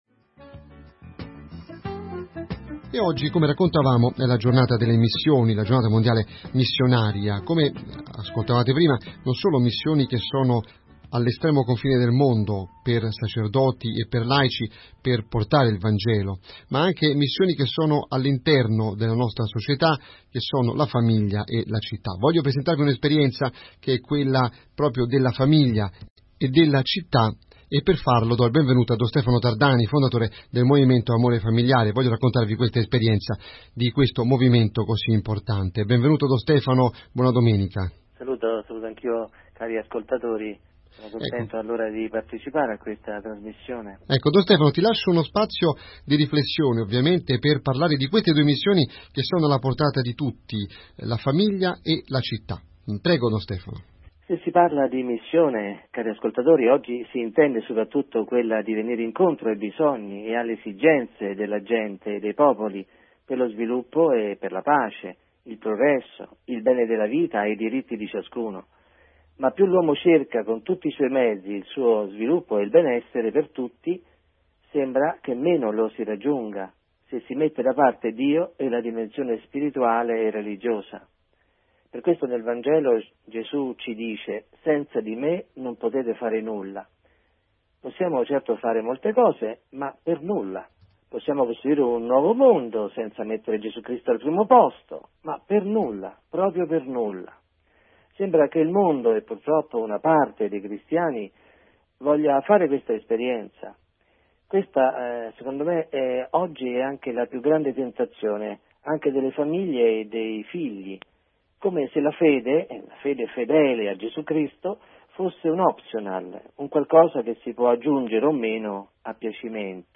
La missione particolare della famiglia - interviste